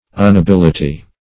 unability - definition of unability - synonyms, pronunciation, spelling from Free Dictionary Search Result for " unability" : The Collaborative International Dictionary of English v.0.48: Unability \Un`a*bil"i*ty\, n. Inability.